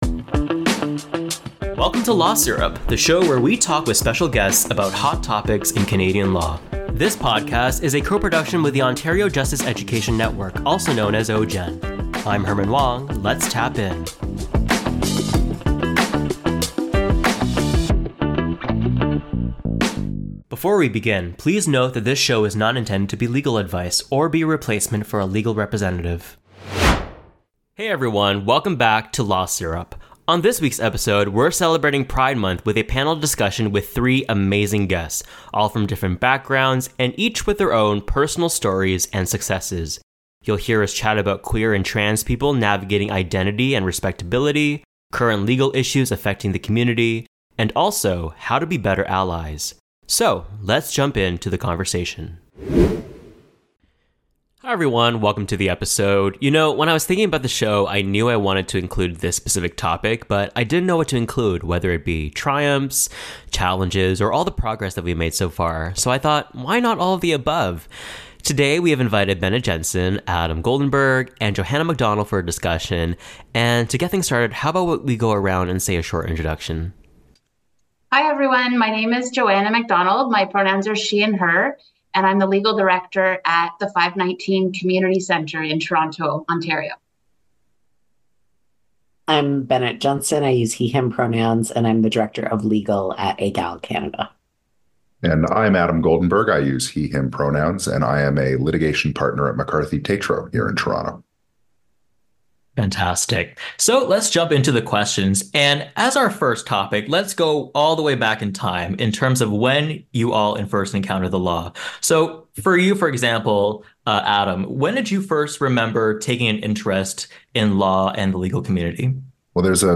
hosts a panel discussion about identifying as a 2SLGBTQ+ individual within the legal profession, issues related to respectability, and how to be a better ally.